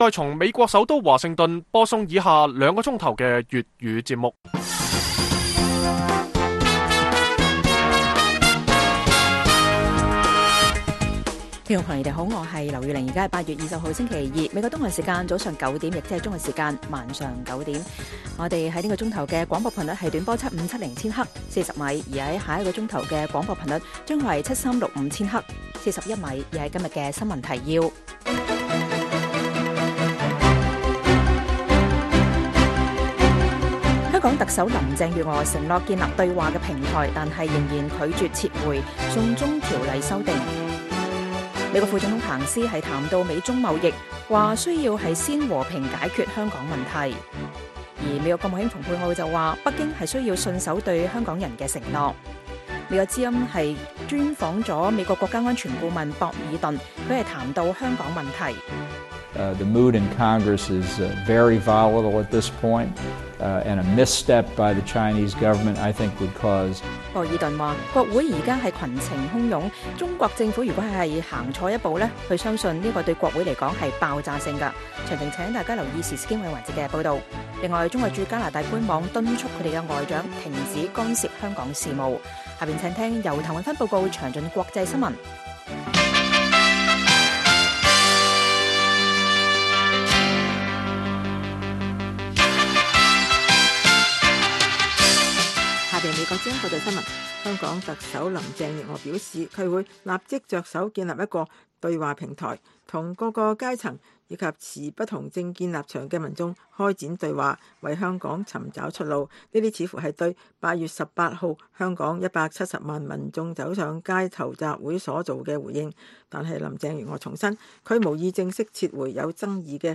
粵語新聞 晚上9-10點
北京時間每晚9－10點 (1300-1400 UTC)粵語廣播節目。